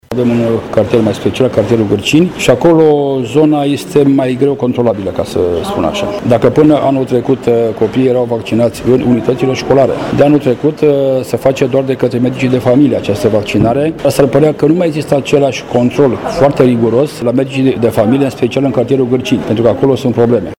Primarul municipiului Săcele, Virgil Popa, are o explicație legată de gradul modest de vaccinare din zonă: